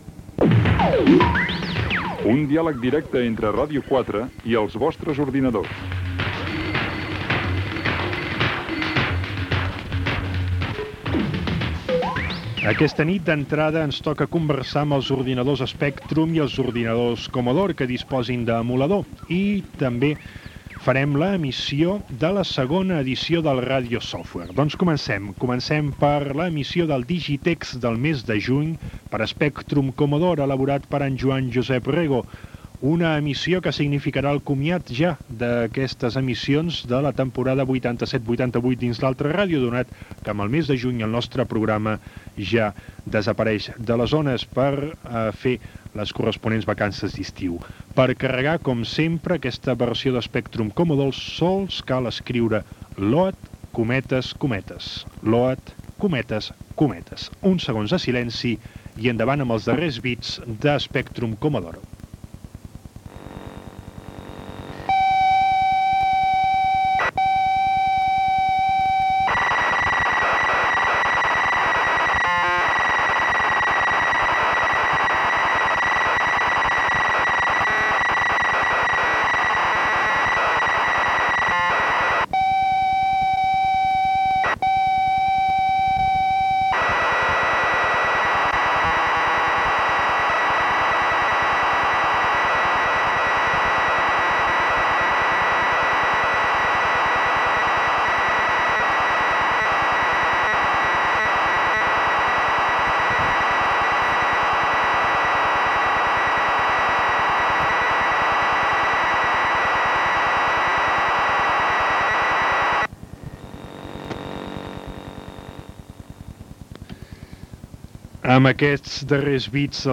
Digitext per a Spectrum i Commodore i emissió de Radiosoftware 11, 12 i 13.
Divulgació
En el temps aproximat d'un minut es radiava el programa, en llenguatge d'ordinadors, que els oïdors, bé fos directament del seu receptor o enregistrant-lo prèviament en una cassette, havien de carregar en el seu ordinador domèstic.